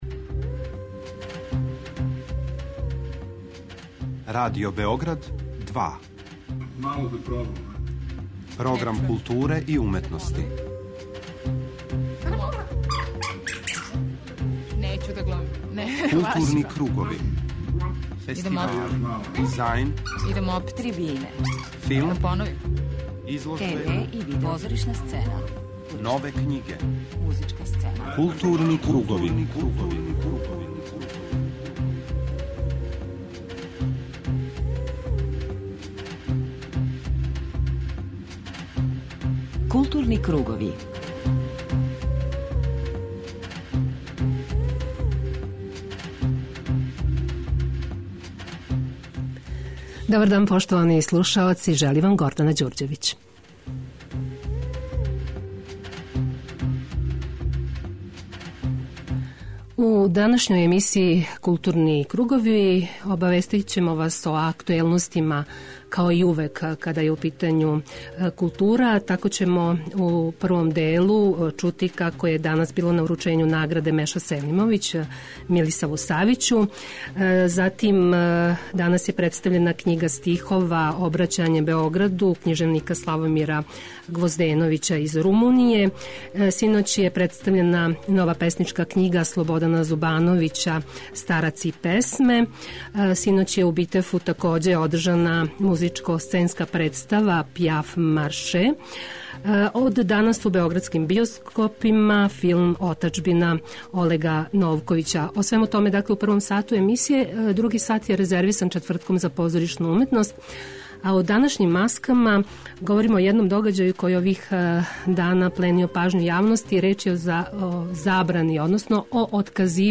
преузми : 41.11 MB Културни кругови Autor: Група аутора Централна културно-уметничка емисија Радио Београда 2.